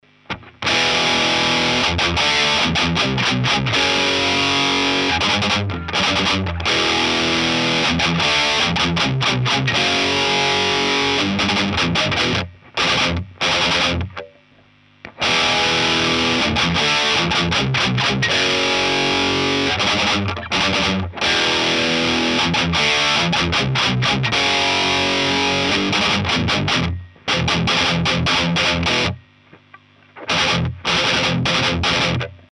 Сравнение 6н2п и 12ах7: